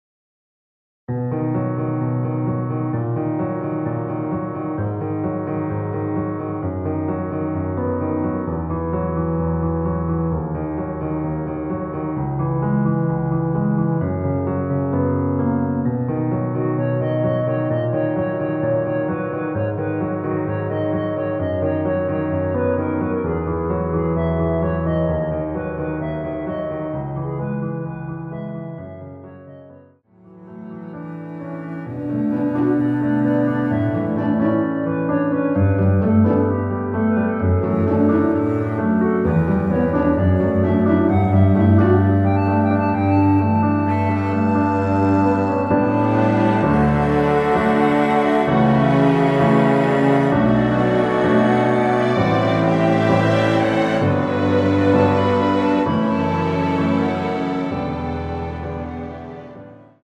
원키에서(-1)내린 멜로디 포함된 MR입니다.(미리듣기 확인)
앞부분30초, 뒷부분30초씩 편집해서 올려 드리고 있습니다.
중간에 음이 끈어지고 다시 나오는 이유는